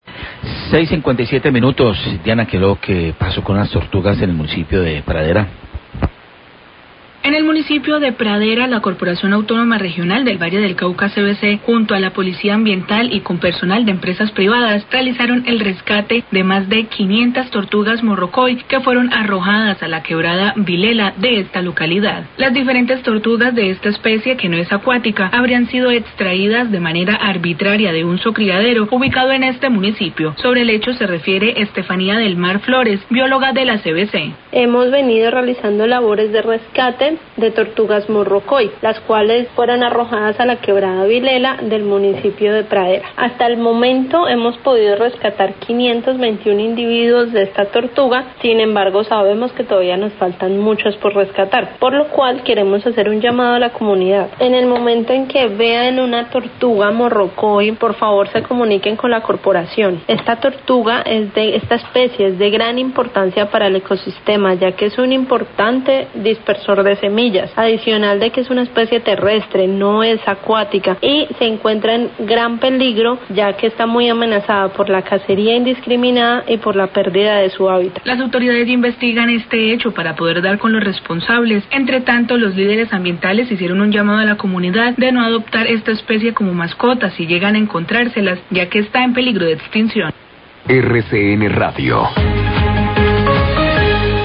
Biologa CVC habla de rescate de tortugas morrocoy arrojadas a un caño en Pradera
Radio